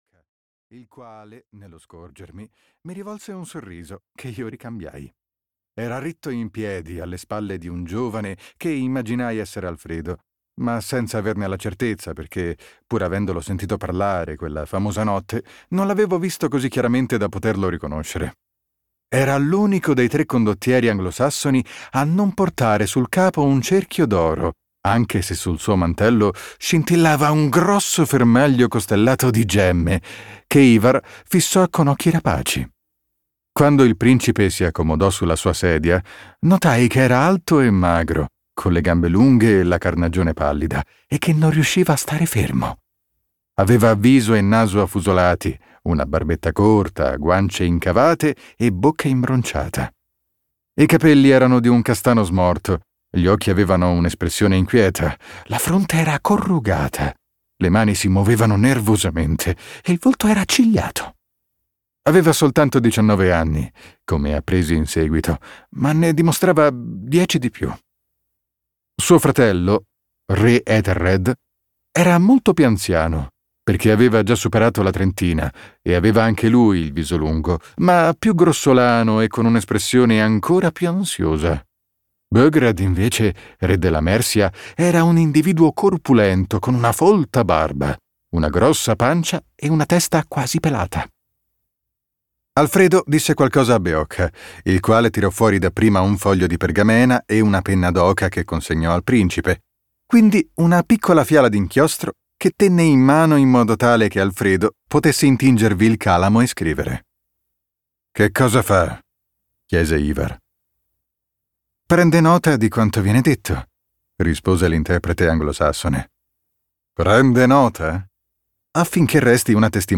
"L'ultimo re" di Bernard Cornwell - Audiolibro digitale - AUDIOLIBRI LIQUIDI - Il Libraio